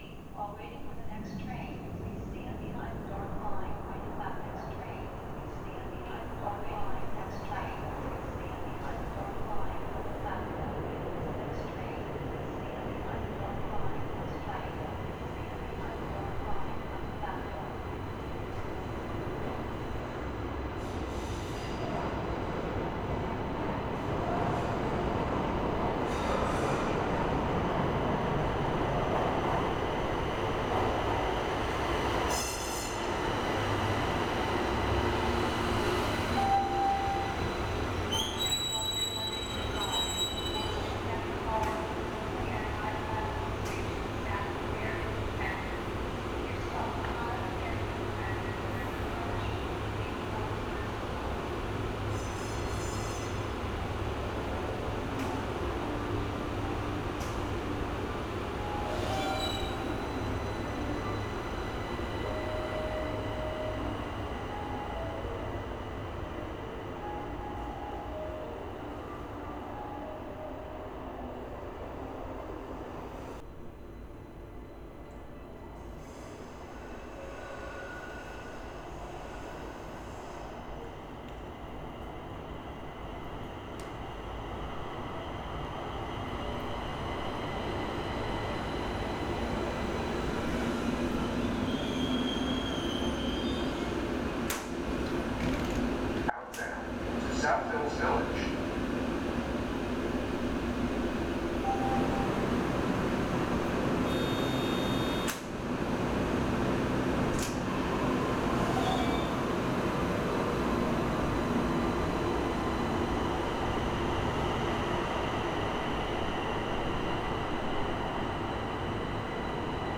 SoundScape: Draft 1
Horrific screeches echoe against the thick, concrete walls. The roar grows louder and louder as it tears around the corner.
soundscape_mixdown.wav